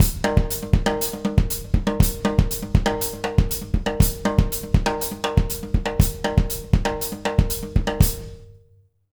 120SALSA01-L.wav